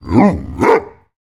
Minecraft Version Minecraft Version latest Latest Release | Latest Snapshot latest / assets / minecraft / sounds / mob / wolf / big / bark2.ogg Compare With Compare With Latest Release | Latest Snapshot
bark2.ogg